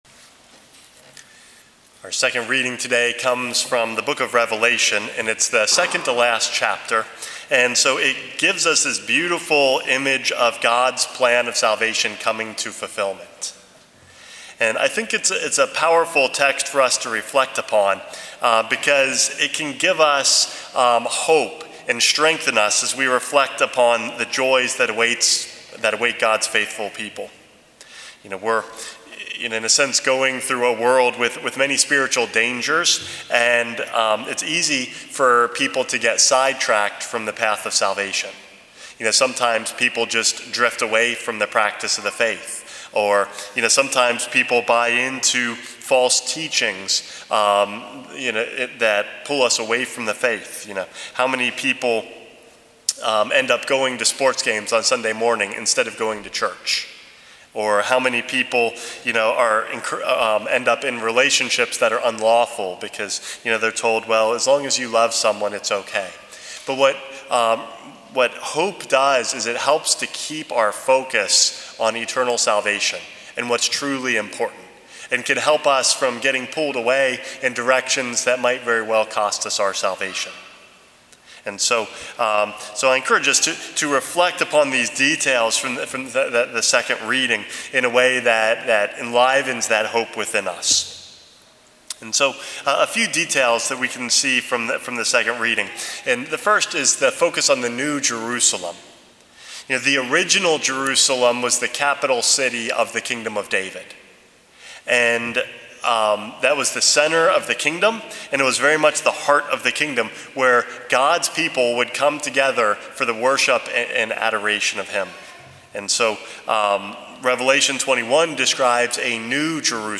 Homily #451 - The New Jerusalem